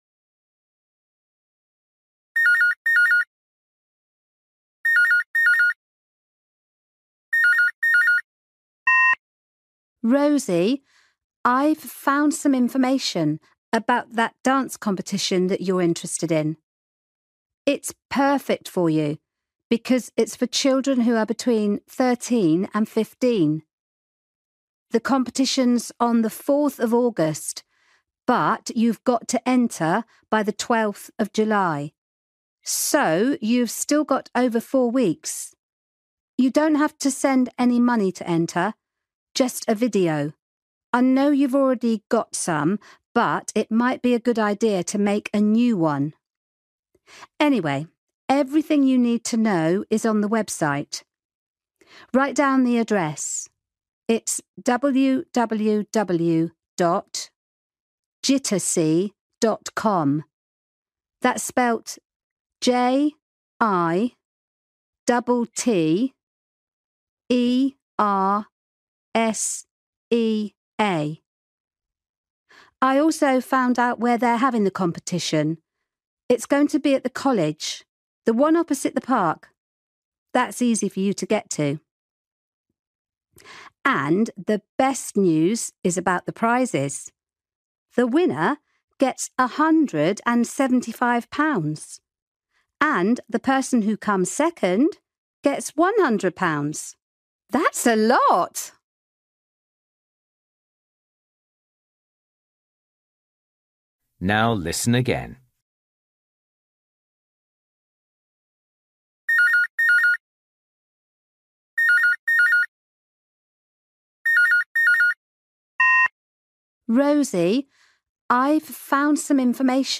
You will hear Rosie’s aunt telling her about a dance competition.